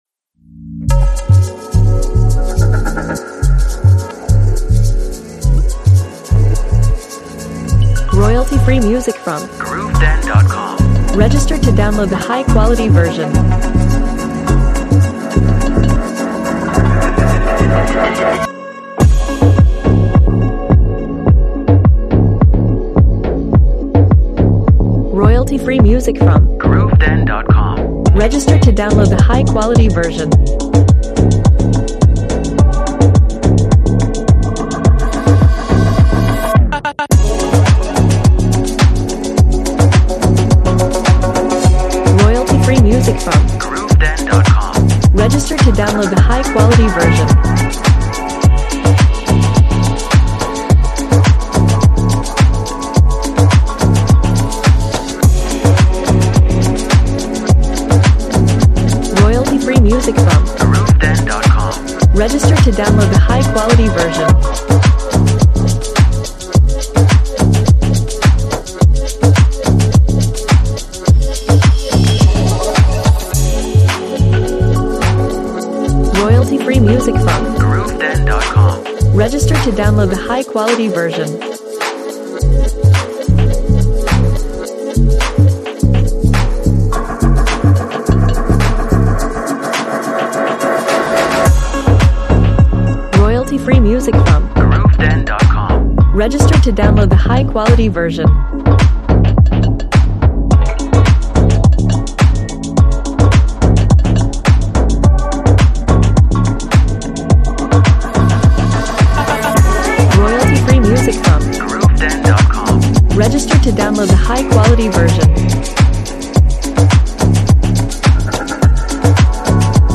A deep and beautiful track with a mood of rest and relax.
Instruments: Drums, pluck, pads, percussion, bass, effects.